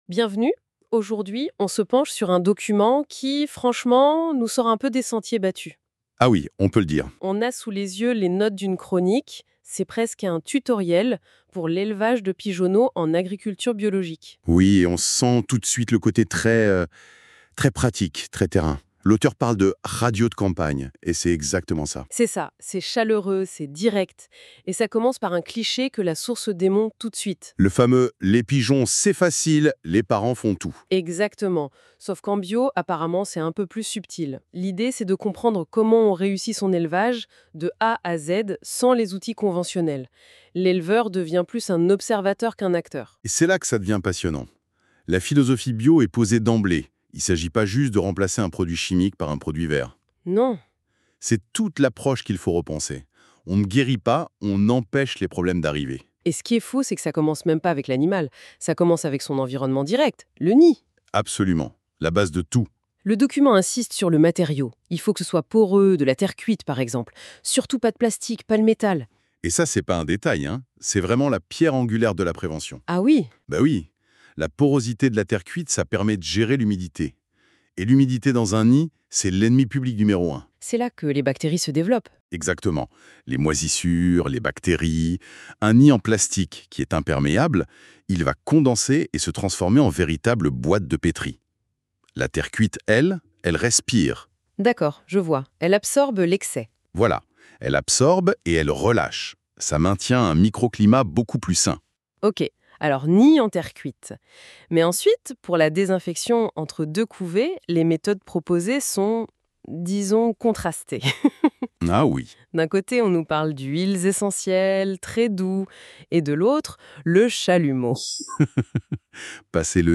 Podcast - écoutez cet article